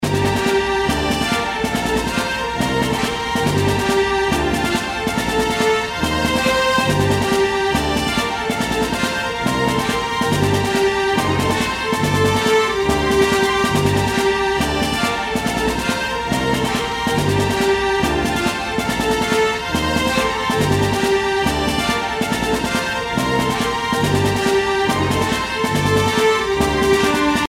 LOOP推奨
楽曲の曲調： HARD
シリアス  ドキドキ